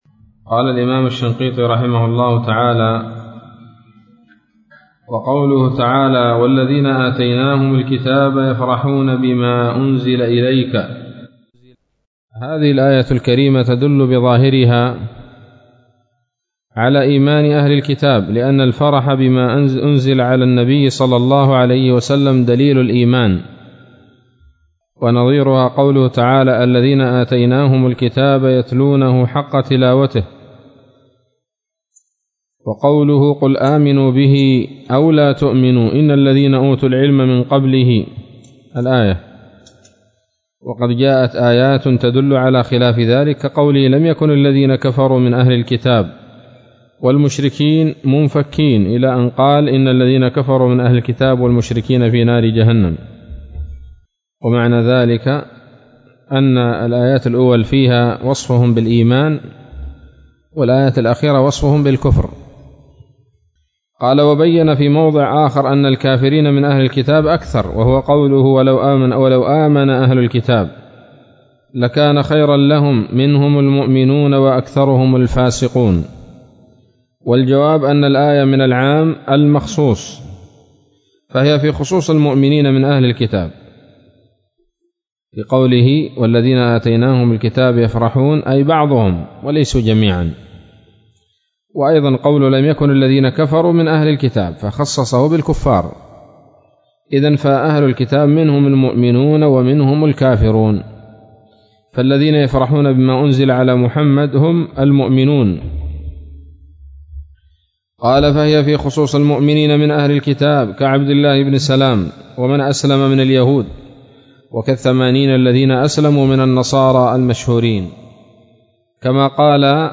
الدرس الثاني والخمسون من دفع إيهام الاضطراب عن آيات الكتاب